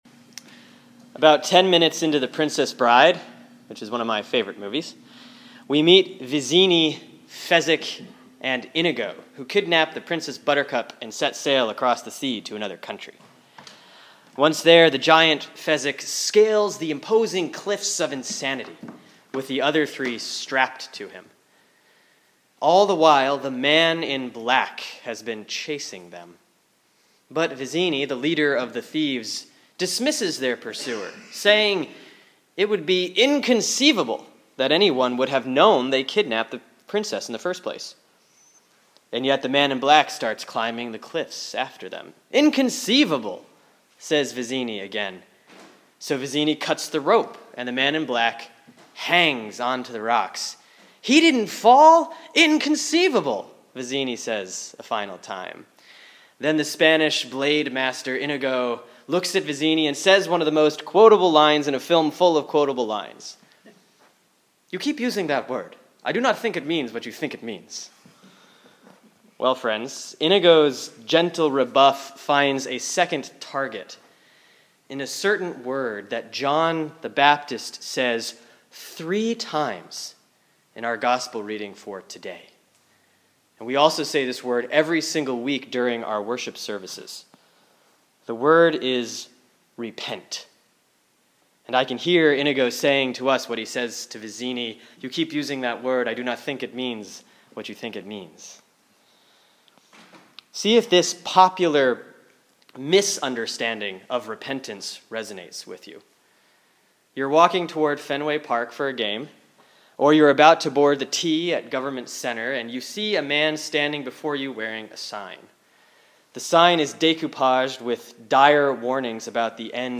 (Sermon for Sunday, December 8, 2013 || Advent 2A || Matthew 3:1-12)